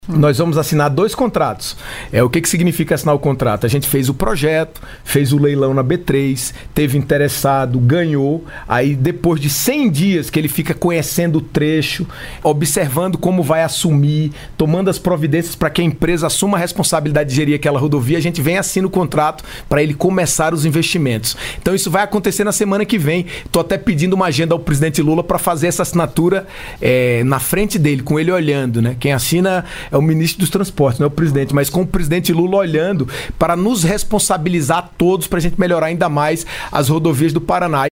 Em entrevista nesta quinta-feira (10) durante o programa “Bom dia, ministro” com participação da CBN Curitiba, o ministro dos Transportes, Renan Filho, anunciou a assinatura de novos contratos da concessão de rodovias no Paraná.
SONORA-MINISTRO-TRANSPORTES-03-CS-1.mp3